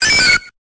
Cri d'Axoloto dans Pokémon Épée et Bouclier.